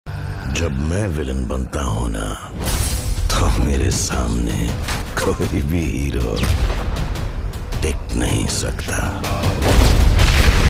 Categories: Bollywood Ringtones, Dialogue Ringtone